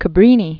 (kə-brēnē), Saint Frances Xavier Known as "Mother Cabrini." 1850-1917.